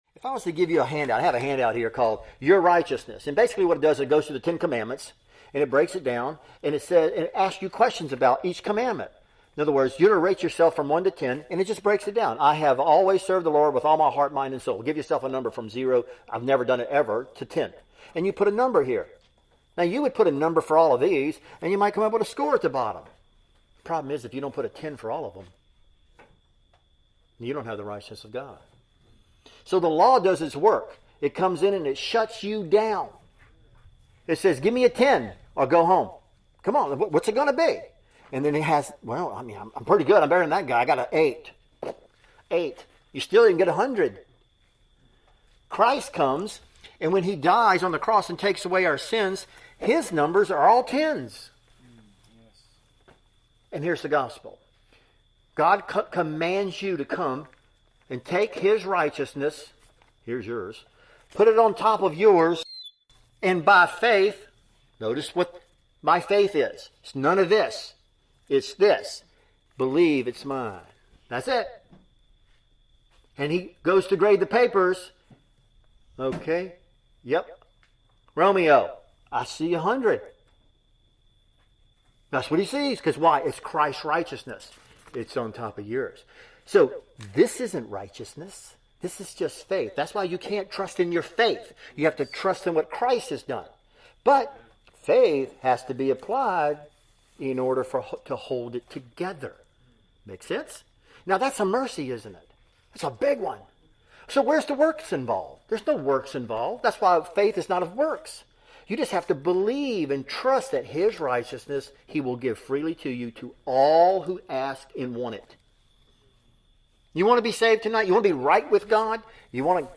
2019 Men's Retreat | 4:27 Excerpt | In order to go to heaven, you must be as holy as God and have a perfect record in keeping all of God's commandments.